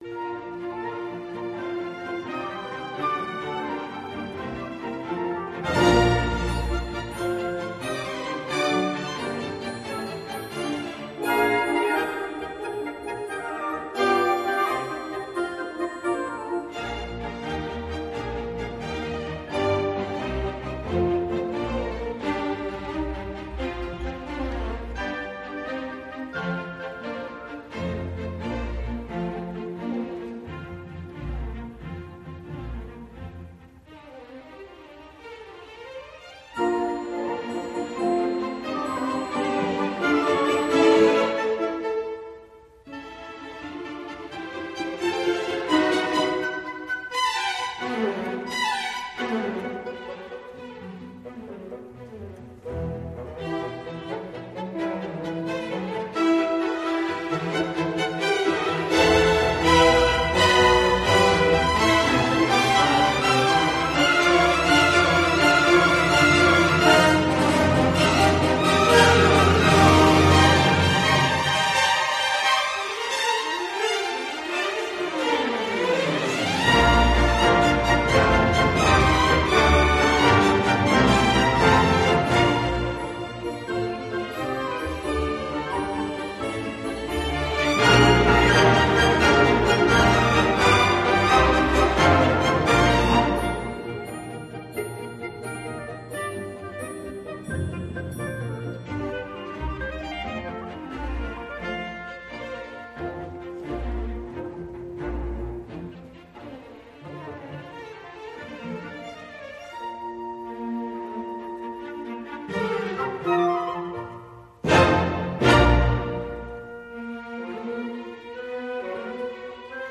La cinquième symphonie est souvent nommée ‘pastorale », d’une part en raison de sa tonalité qui est la même que dans la sixième de Beethoven, mais aussi de son caractère bucolique du moins dans les trois premiers mouvements. Le scherzo est un petit bijou à lui tout seul :